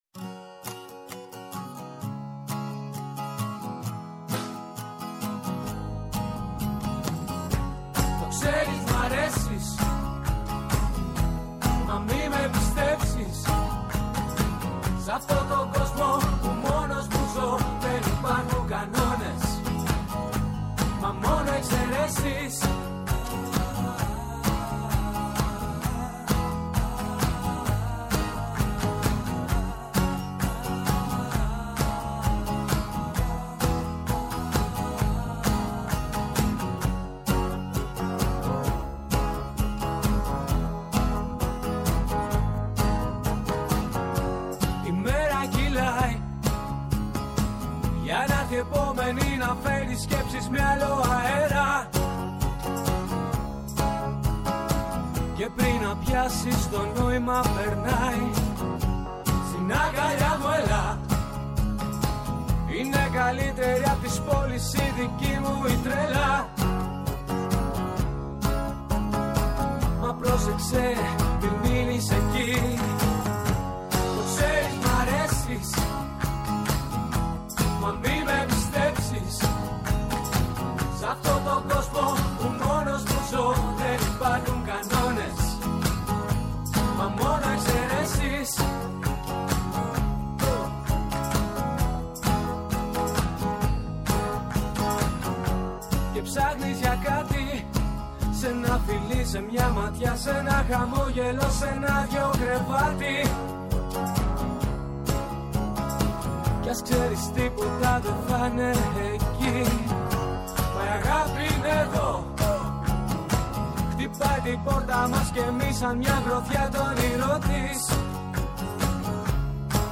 Καλεσμένος στο στούντιο ο συγγραφέας και κριτικός λογοτεχνίας Αρης Μαραγκόπουλος.